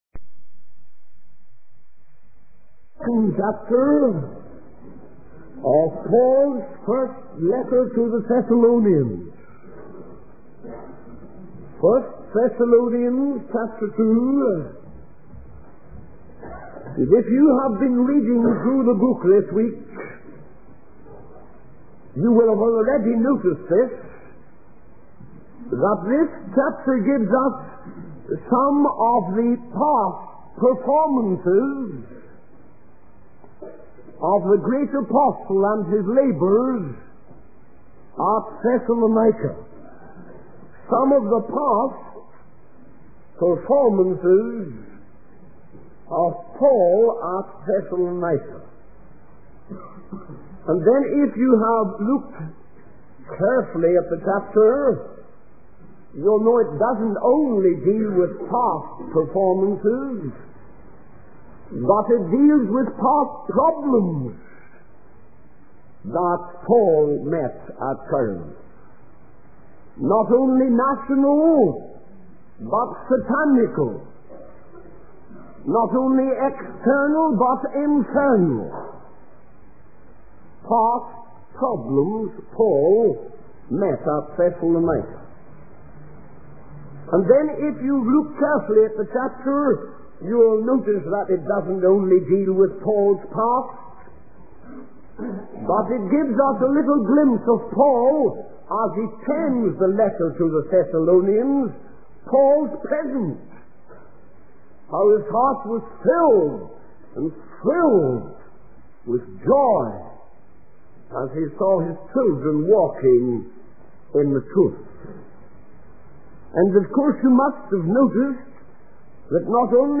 In this sermon, the preacher emphasizes the good news of God's love and the sacrifice of Jesus on the cross.